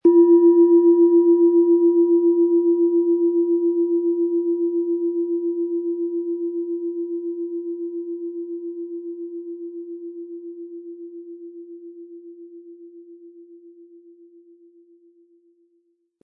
Planetenton
Die Klangschale wurde nach alter Tradition in einer kleinen Manufaktur handgefertigt.
Im Sound-Player - Jetzt reinhören können Sie den Original-Ton genau dieser Schale anhören.
Sanftes Anspielen wird aus Ihrer bestellten Klangschale mit dem beigelegten Klöppel feine Töne zaubern.
MaterialBronze